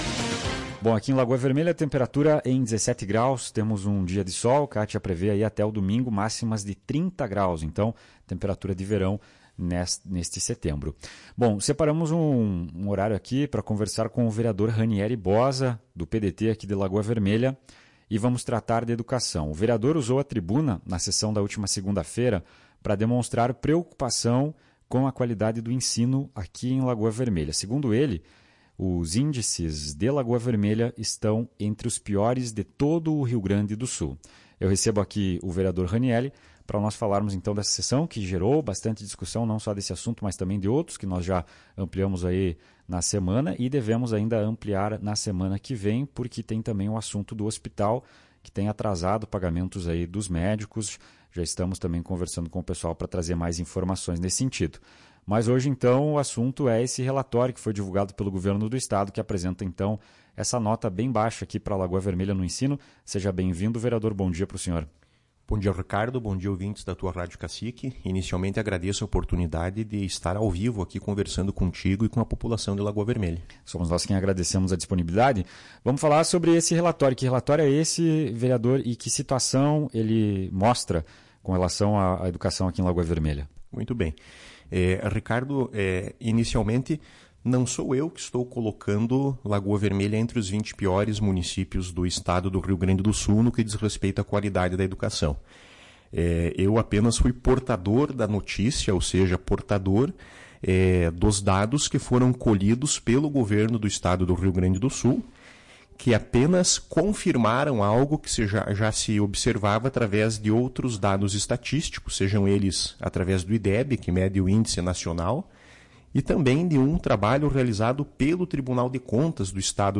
Na sessão da Câmara de Vereadores de Lagoa Vermelha realizada na segunda-feira, 11/09, o vereador Ranyeri Bozza, do PDT, usou a tribuna para expressar sua preocupação com a qualidade da educação no município. Segundo Bozza, os índices educacionais da cidade estão entre os piores do Estado do Rio Grande do Sul.